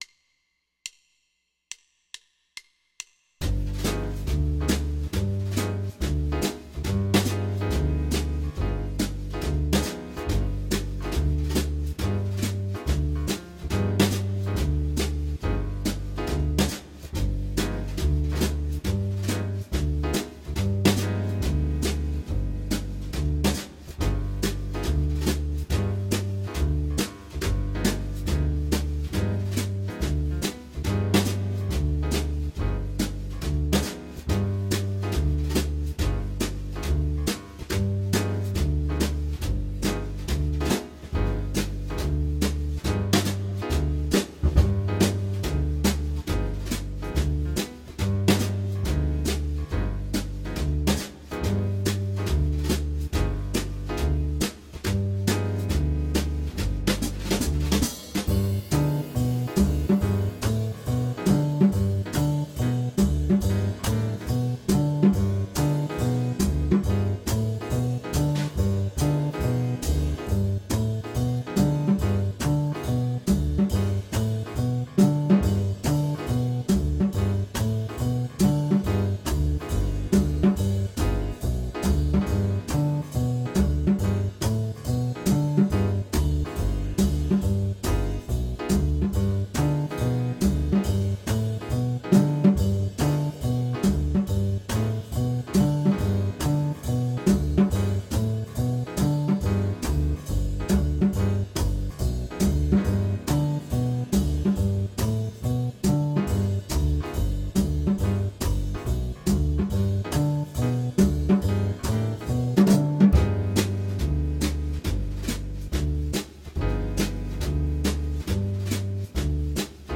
Here’s a backing track in the key of G you can use to practice this really cool-sounding scale.
G7b9b13-1-chord-groove-Render.m4a